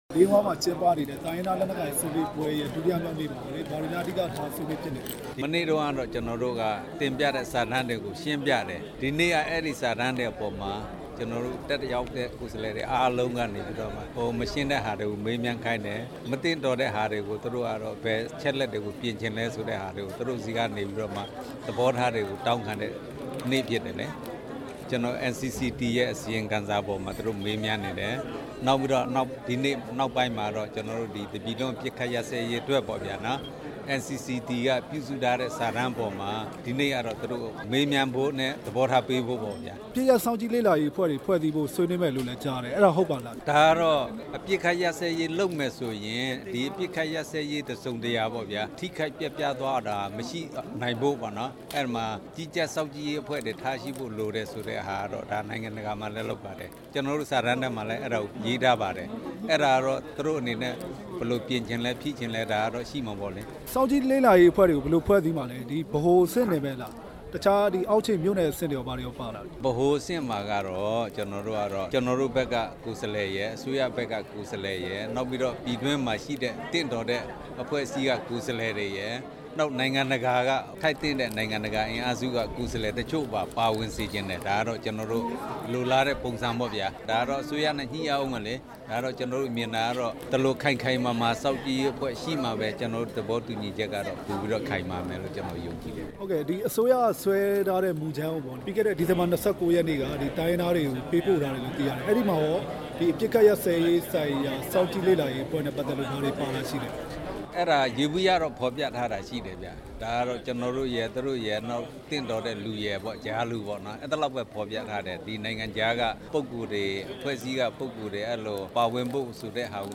ဒီကနေ့ ညီလာခံခေတ္တ ရပ်နားစဉ် တွေ့ဆုံမေးမြန်းထားတာ နားဆင်နိုင်ပါတယ်။